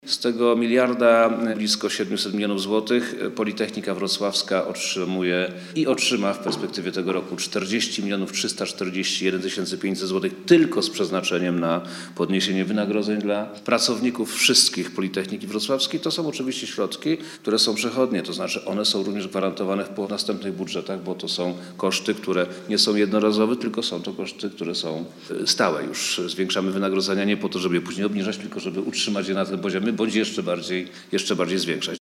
-Politechnika Wrocławska otrzyma ponad 40 mln złotych, mówi minister Edukacji i Nauki – Przemysław Czarnek.